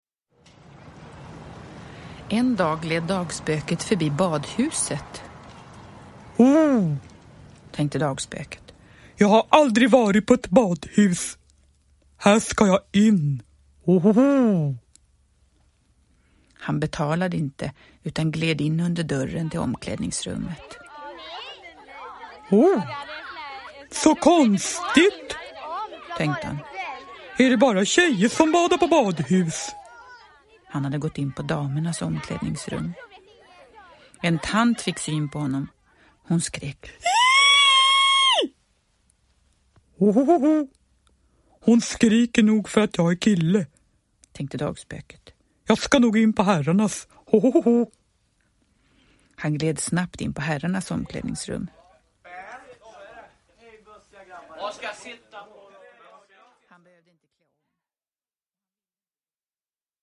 Dagspöket på badhuset – Ljudbok – Laddas ner